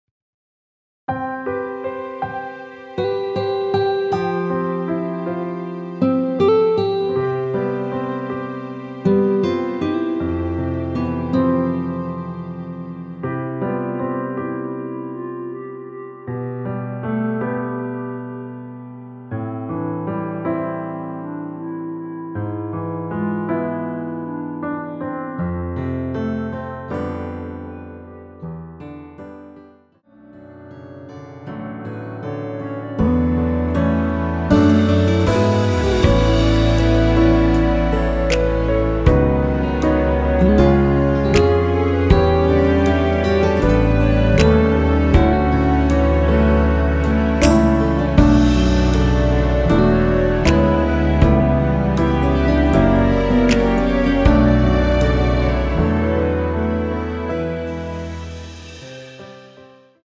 원키에서(-4)내린 멜로디 포함된 MR입니다.(미리듣기 확인)
앞부분30초, 뒷부분30초씩 편집해서 올려 드리고 있습니다.
중간에 음이 끈어지고 다시 나오는 이유는